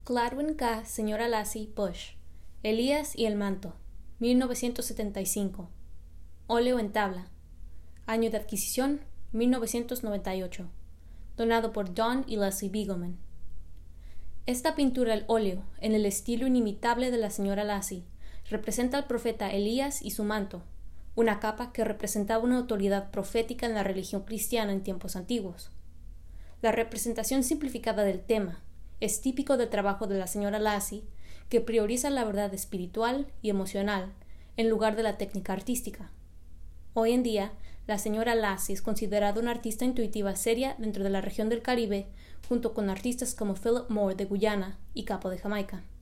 (English) Gladwyn K. Bush - Elijah and the Mantle Voiceover (Español) Gladwyn K. Bush - Elías y el Manto Narración